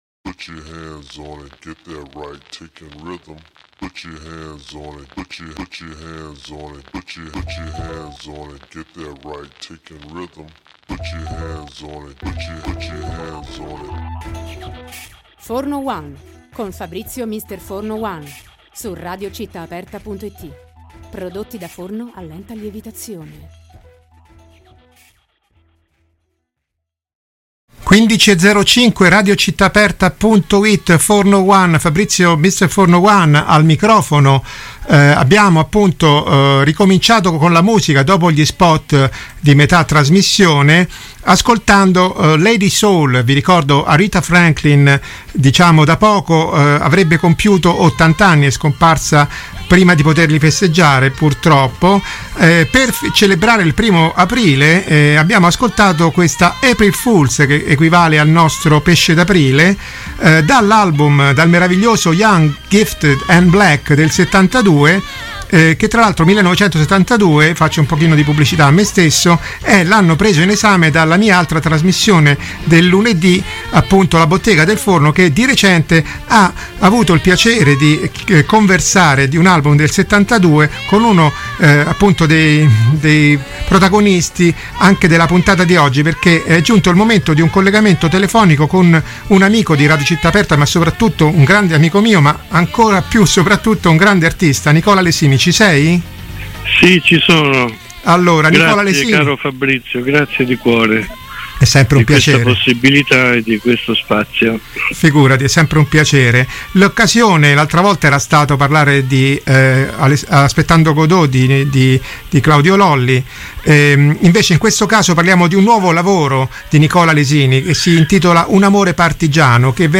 Al termine della chiacchierata si è passati all’ascolto di un estratto da Un amore partigiano, intitolato Il ballo di maria e manno